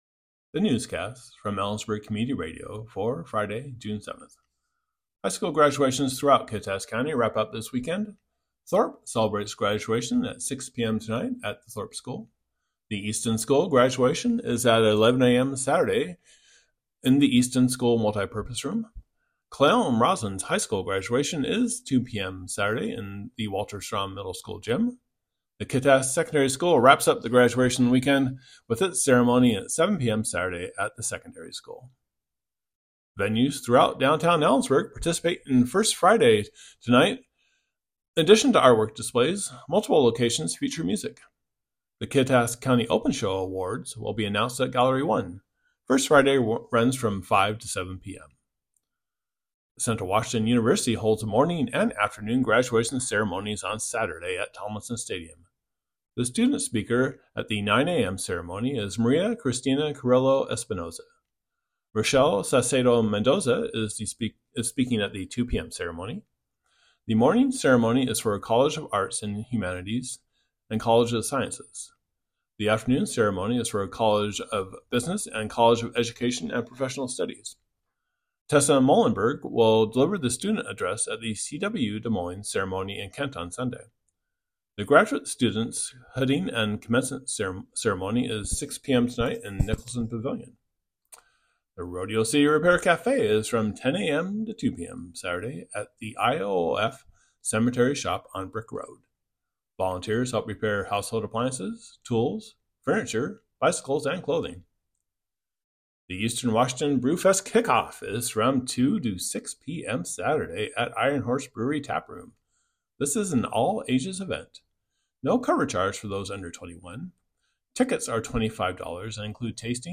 Click here to listen to today's newscast.